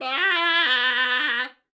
1.21.4 / assets / minecraft / sounds / mob / goat / scream4.ogg
scream4.ogg